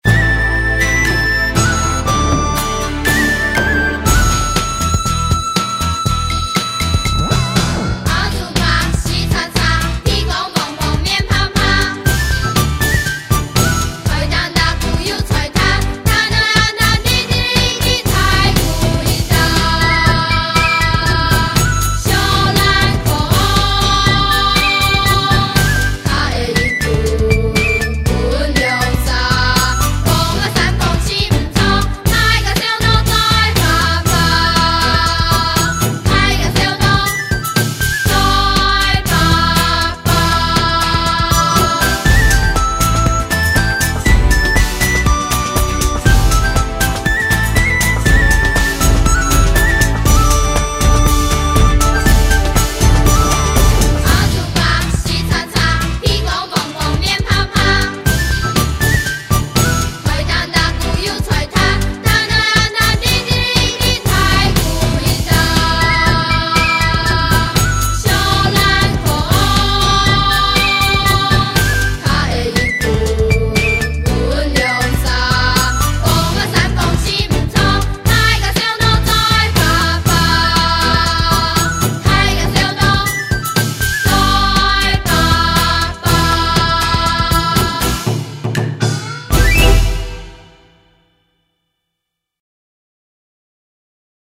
阿丑伯(完整演唱版) | 新北市客家文化典藏資料庫